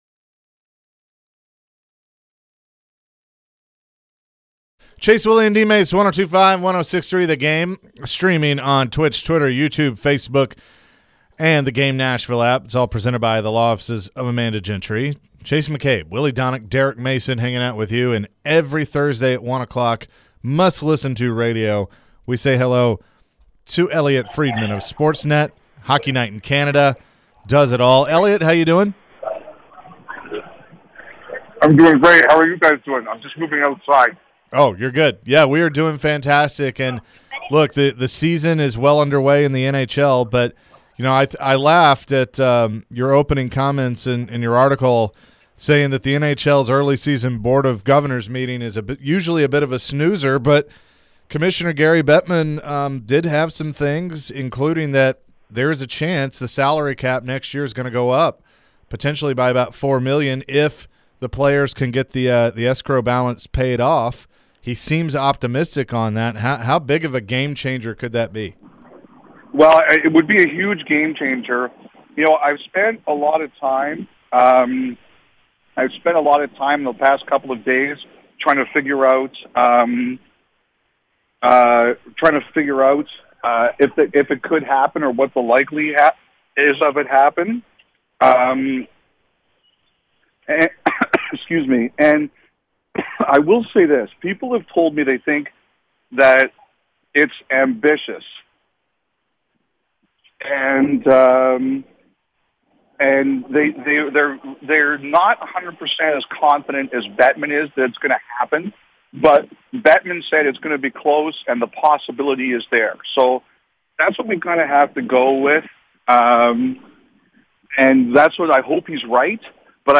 Elliotte Friedman Full Interview (10-20-22)